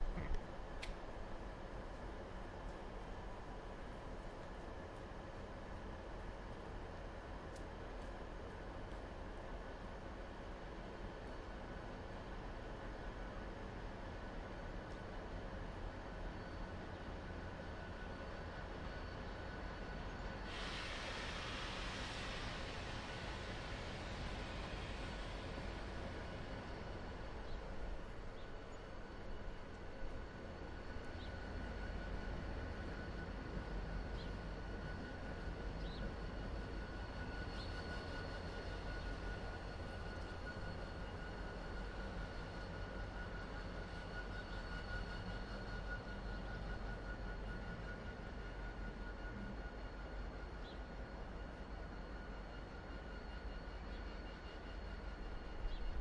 描述：Zoom H1 Zagreb Train station morning commuter trains
标签： station deisel train railway rail
声道立体声